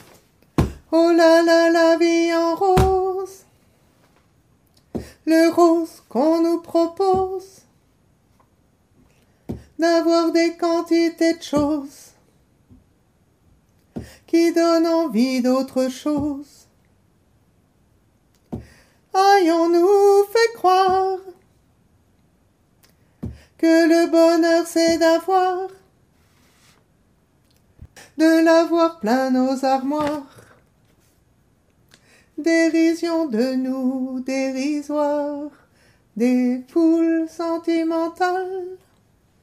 Couplet 1 basses